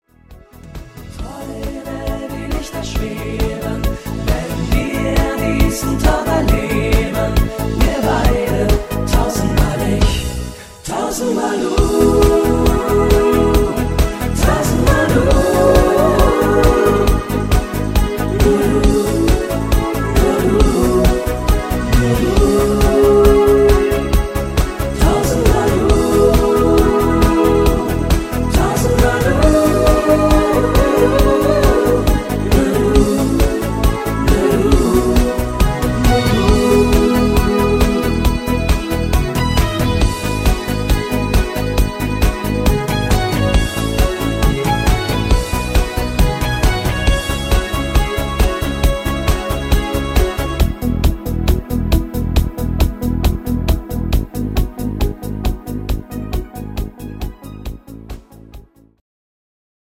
Party Version